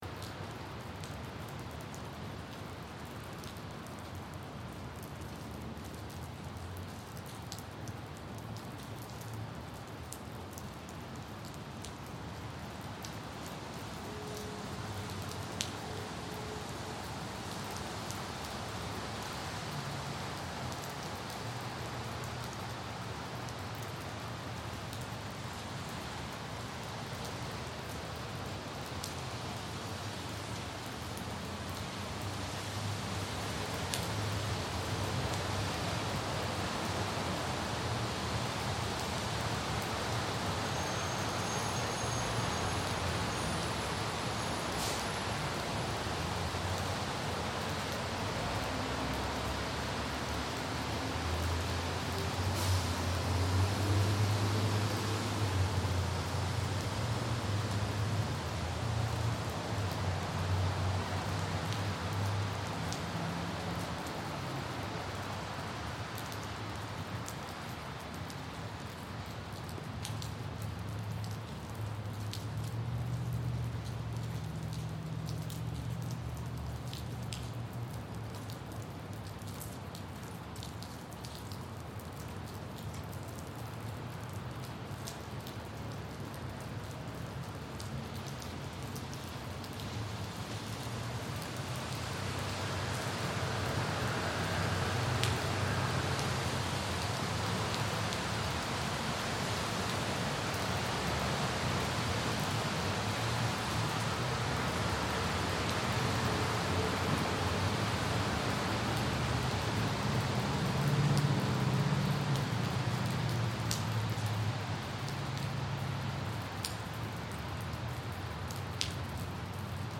Urban Rain Ambience for Fast Relaxation — 1 Hour